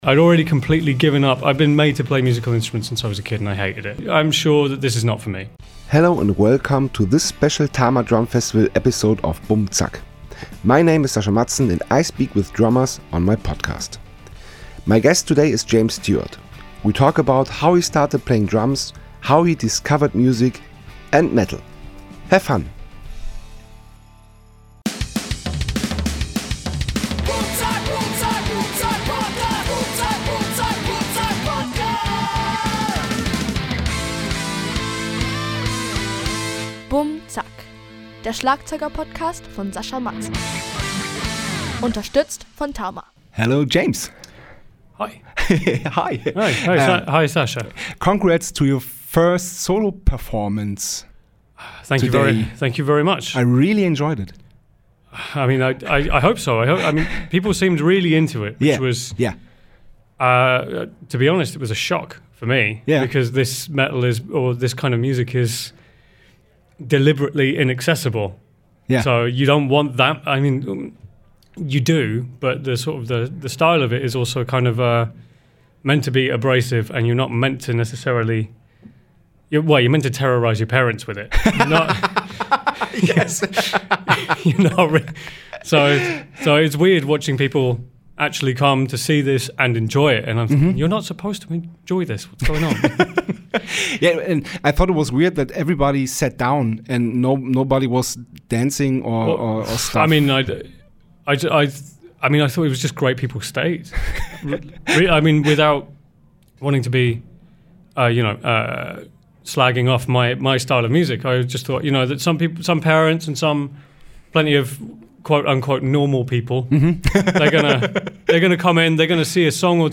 Juni 2024 Nächste Episode download Beschreibung Teilen Abonnieren Hello and welcome to this special Tama Drumfestival episode of BummZack.